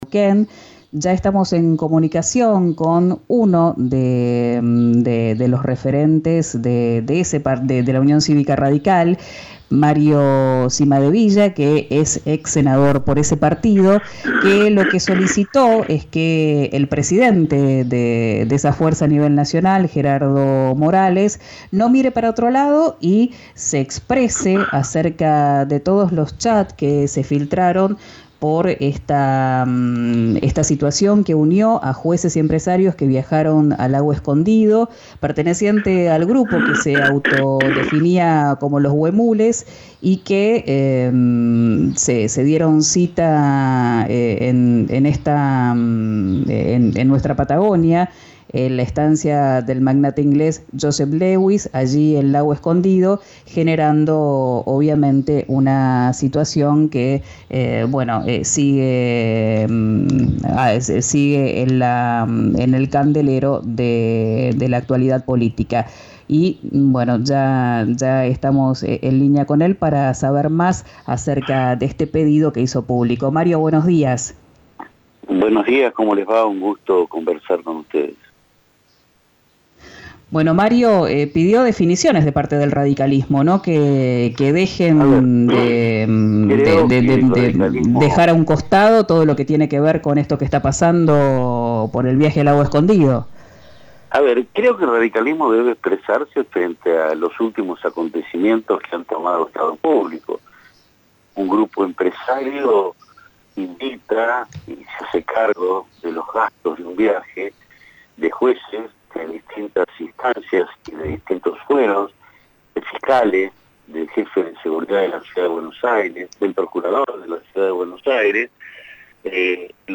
Escuchá al exsenador Mario Cimadevilla en “Quién Dijo Verano”, por RÍO NEGRO RADIO: